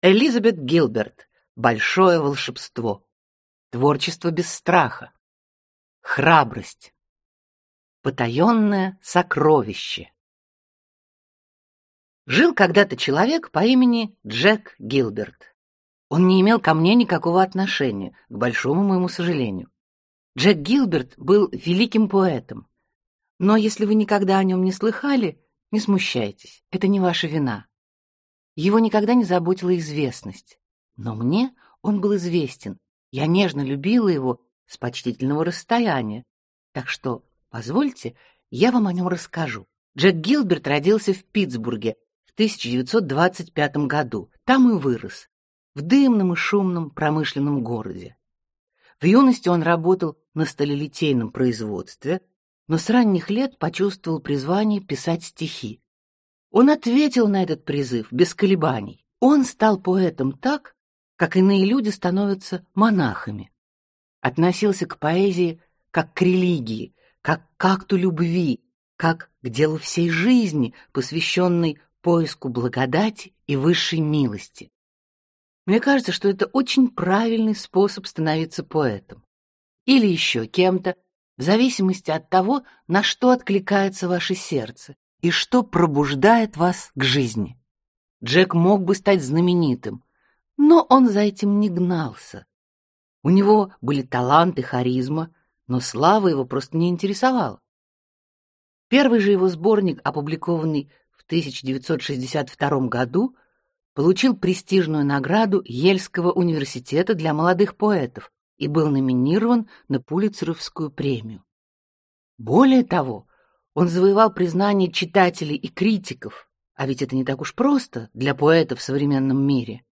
Аудиокнига Большое волшебство | Библиотека аудиокниг